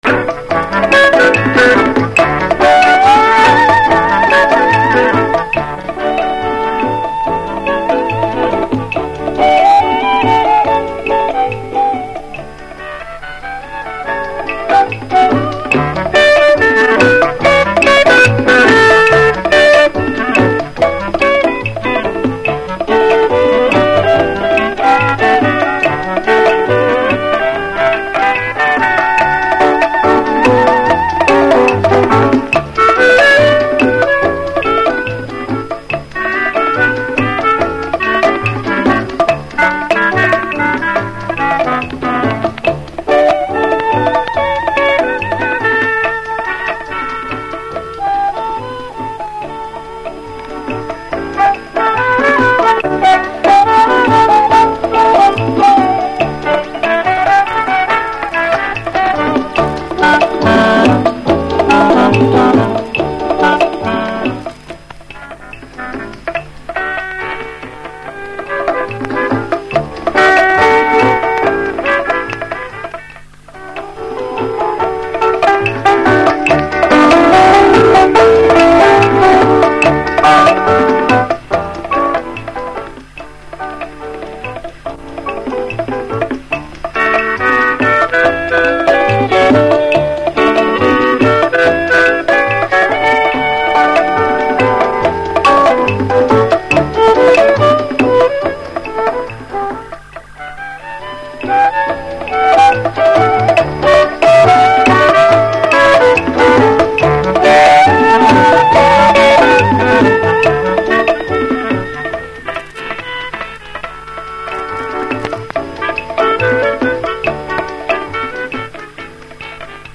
Инструментальная версия. Запись 60-х годов
rumba-prodavets-orehov-(zapis-60-h-godov).mp3